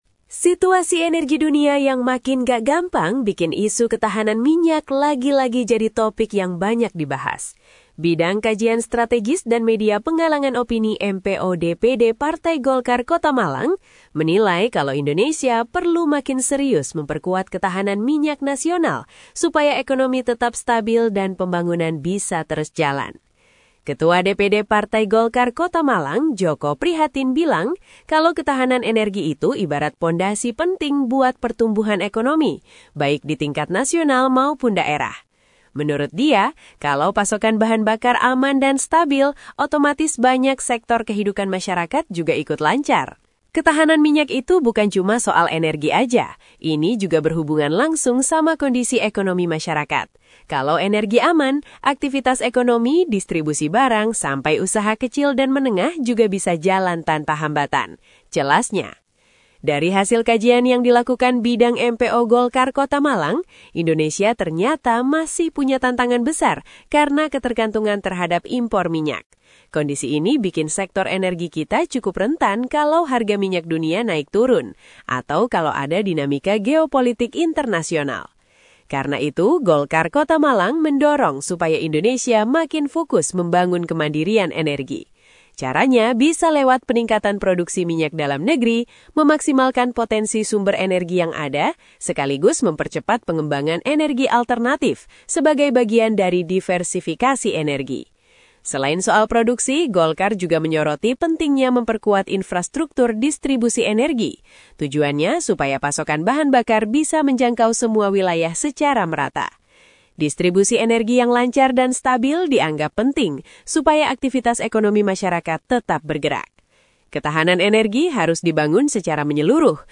Narator Digital